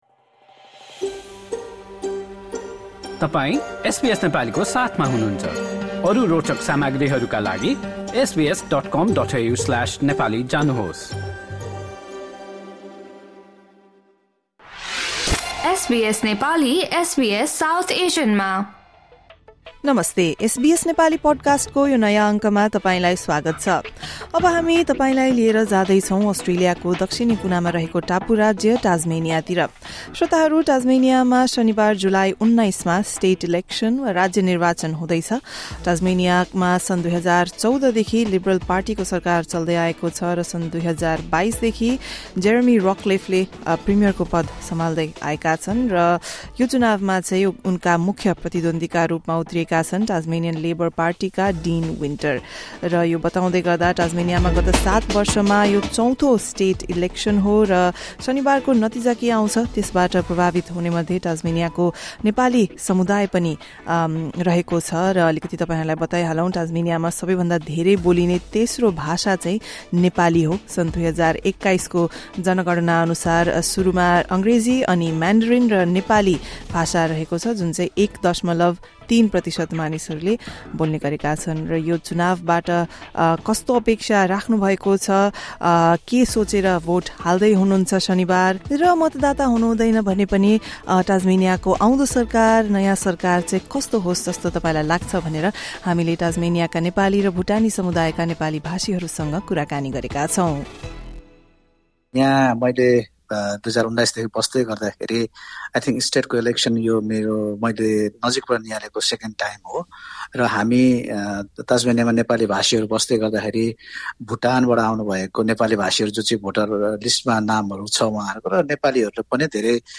यसपालिको निर्वाचनमा कस्ता प्राथमिकताहरूलाई ध्यानमा राखेर भोट हाल्दै हुनुहुन्छ र नयाँ सरकारबाट रहेका अपेक्षाहरूबारे राज्यका नेपाली र भुटानी समुदायका सदस्यहरूसँग एसबीएस नेपालीले गरेको कुराकानी सुन्नुहोस्।